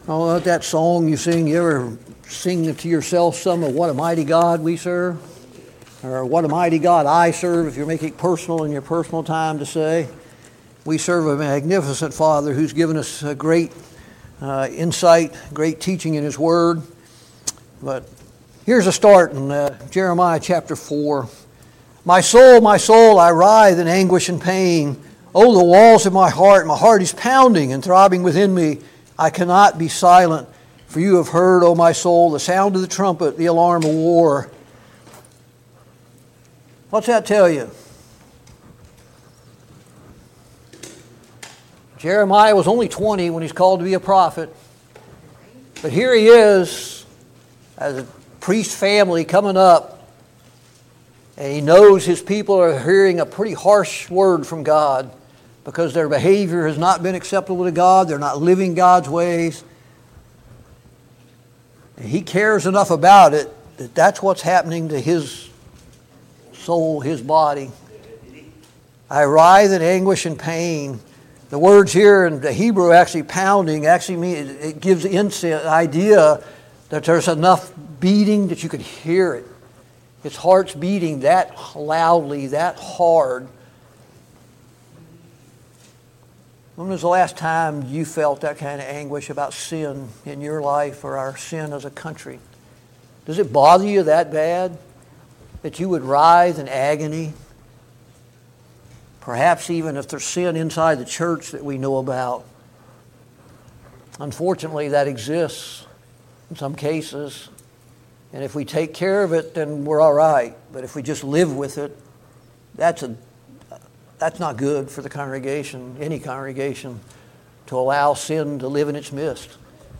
Passage: Jeremiah 4-5 Service Type: Sunday Morning Bible Class « Study of Paul’s Minor Epistles